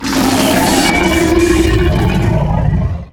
bighurt2.wav